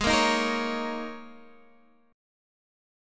G#sus2sus4 Chord